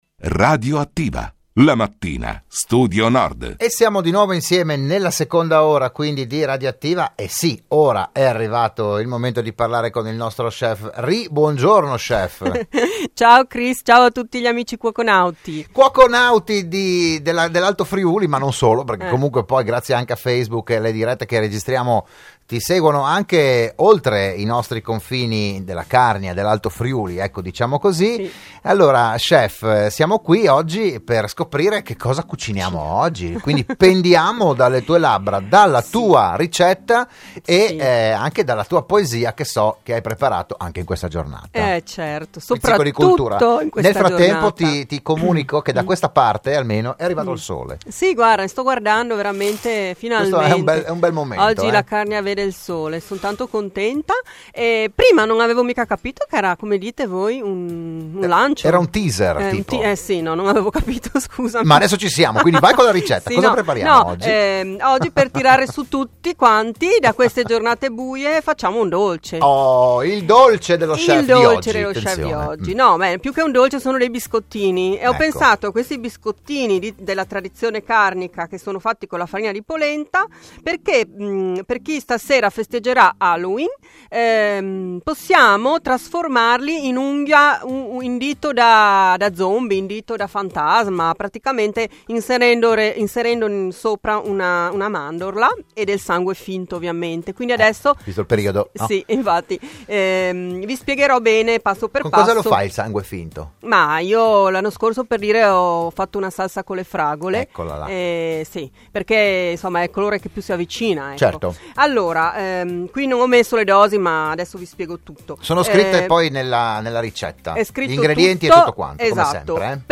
la trasmissione di Radio Studio Nord